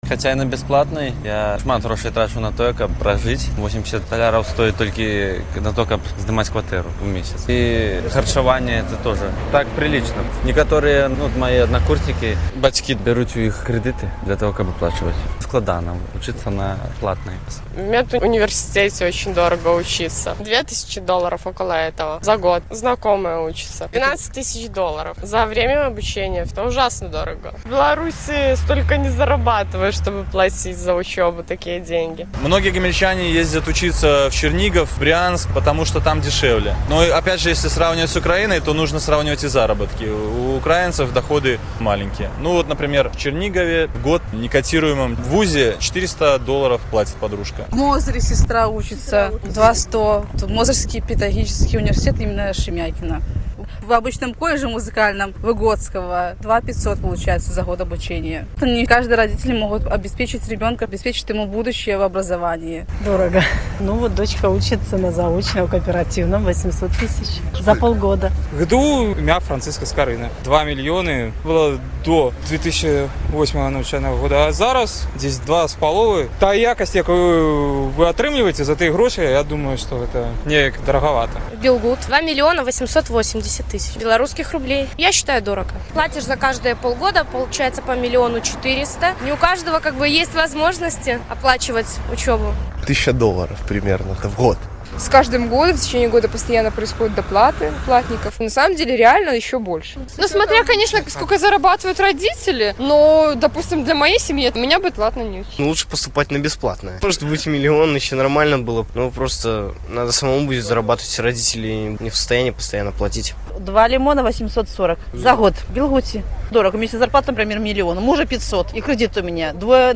Апытаньне моладзі ў Гомелі